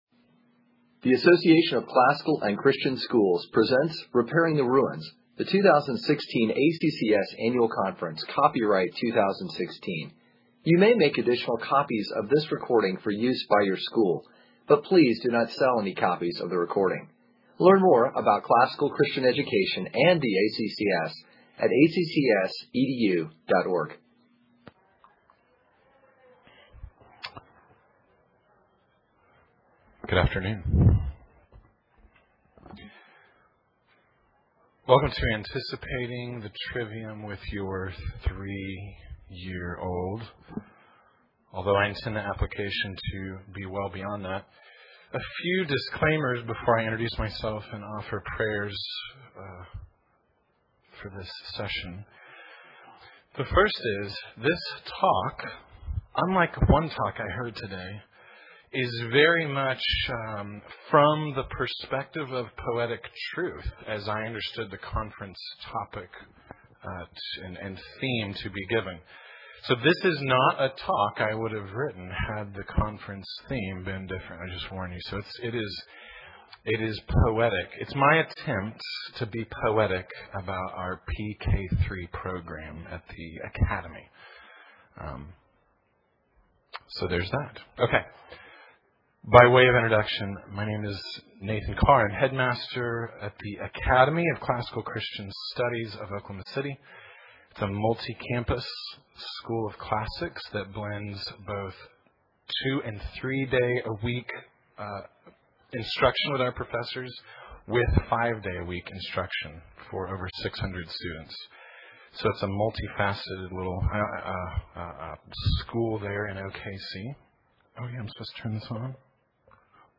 2016 Workshop Talk | 1:02:05 | K-6, History, Latin, Greek & Language, Literature